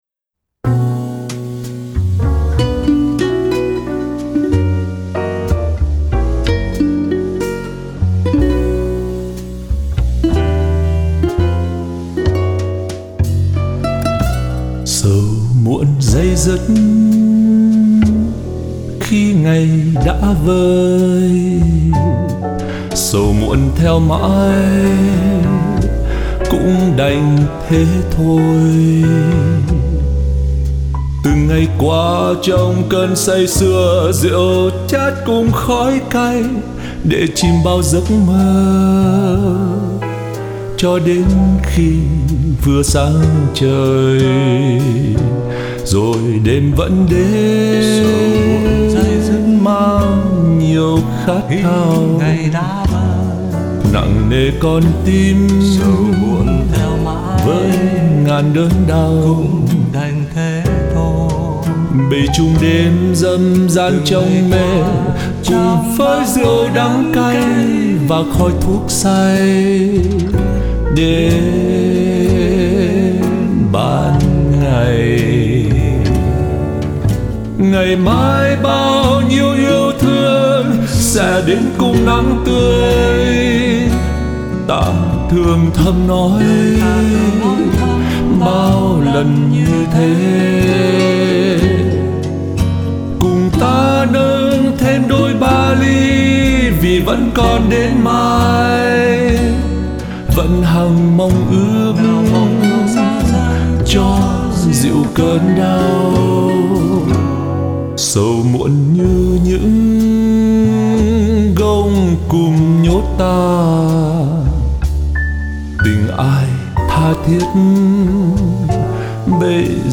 Sau cùng tôi nẩy ra ý hát đuổi theo thành một giọng bè khác.